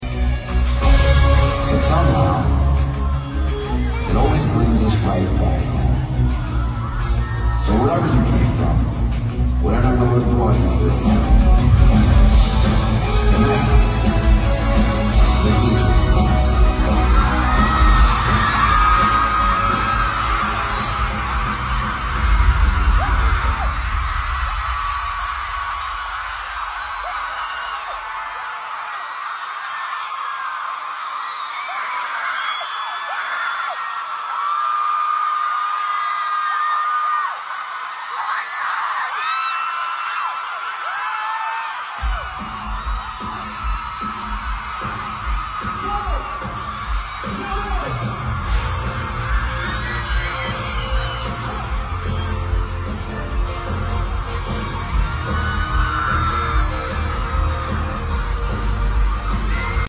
Dos Equis Pavilion in Dallas, TX